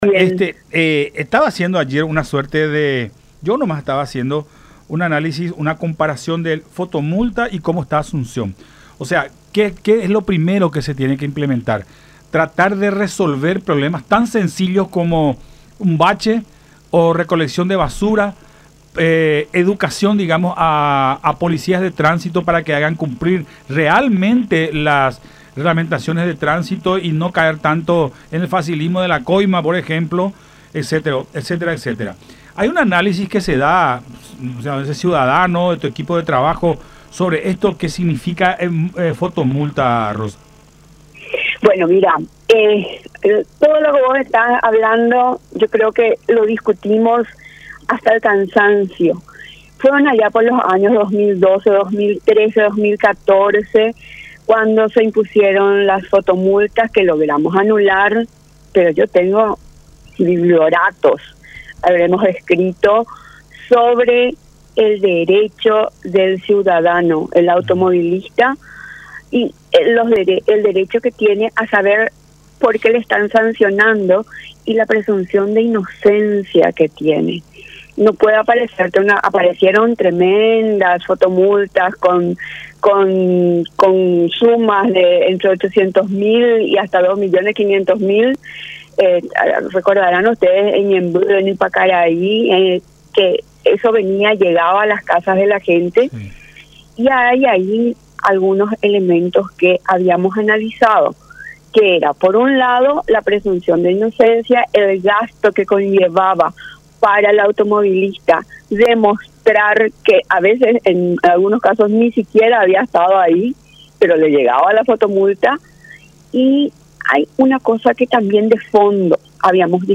en diálogo con Todas Las Voces a través de La Unión.